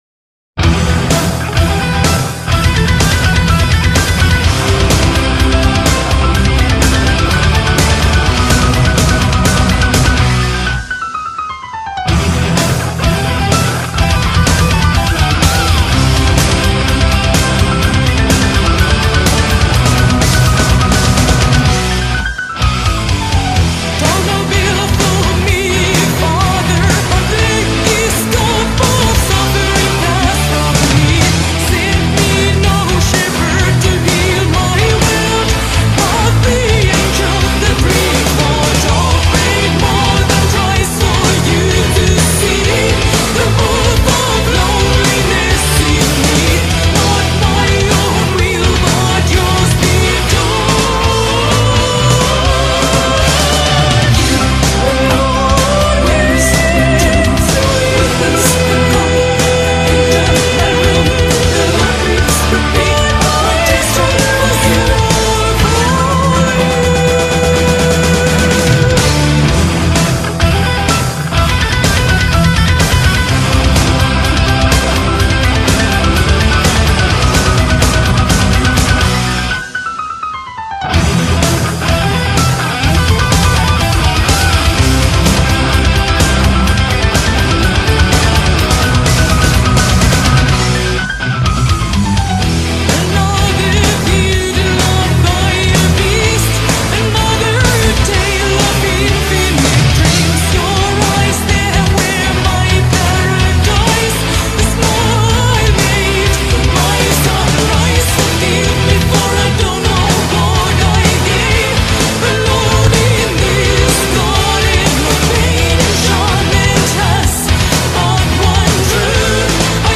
Красиво. Мощно.